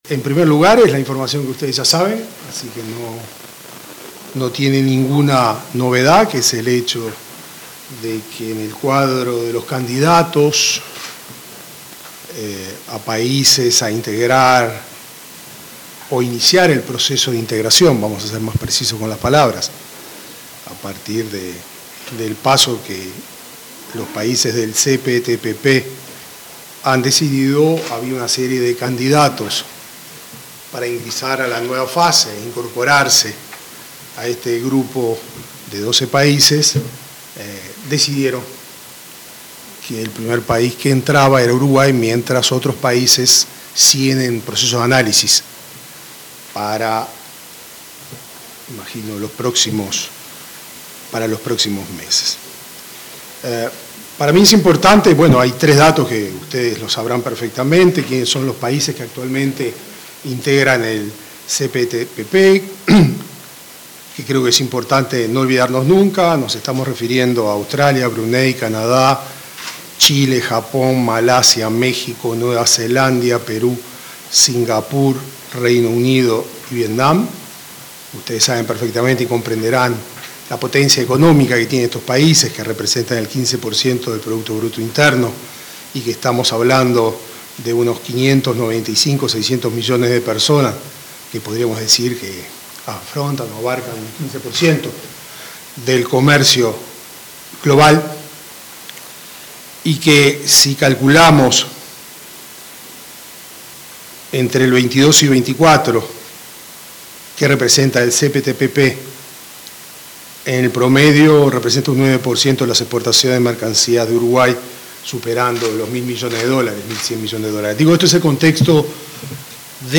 Palabras del canciller, Mario Lubetkin, y la vicecanciller Valeria Csukasi 21/11/2025 Compartir Facebook X Copiar enlace WhatsApp LinkedIn El ministro de Relaciones Exteriores, Mario Lubetkin, y la vicecanciller, Valeria Csukasi, se expresaron en la conferencia de prensa realizada en la sede de la cancillería sobre la nueva fase de negociaciones para el ingreso al Tratado Integral y Progresista de Asociación Transpacífico (CPTPP por sus siglas en inglés).